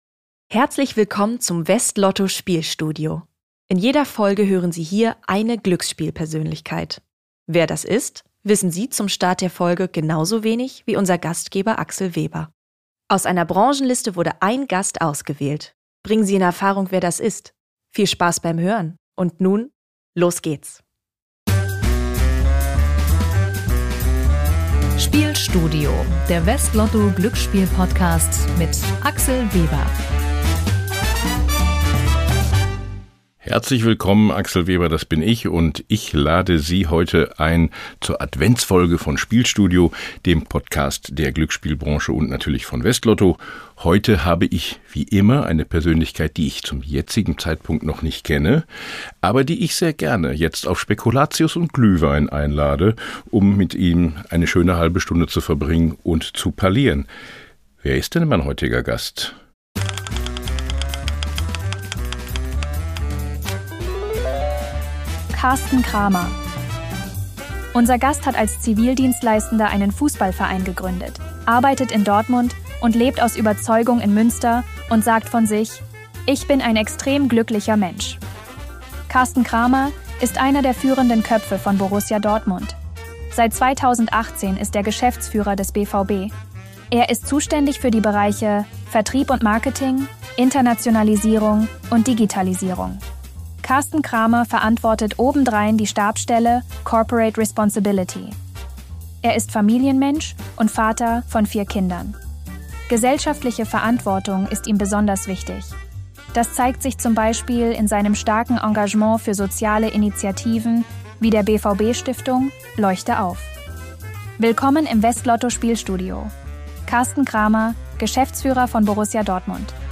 Hinweis: Ein Zuspieler in diesem Podcast wurde mit einer künstlichen Stimme erzeugt, die von einem KI-System erstellt wurde.